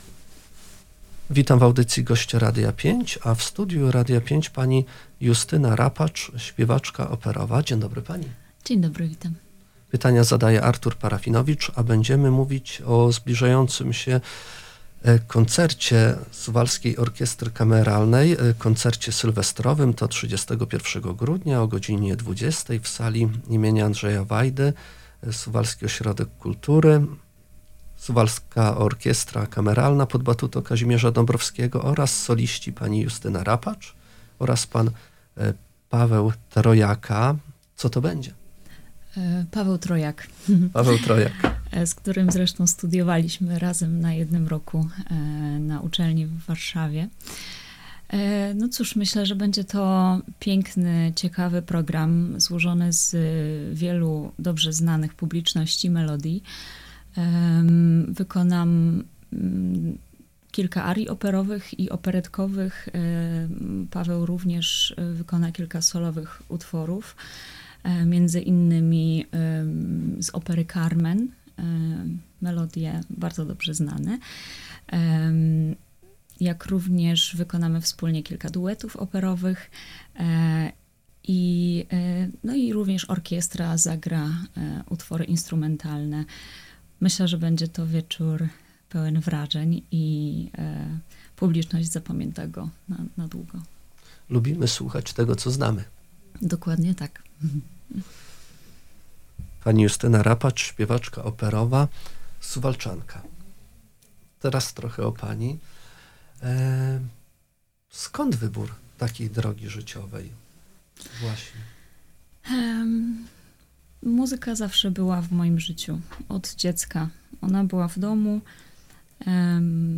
Poniżej cała rozmowa: https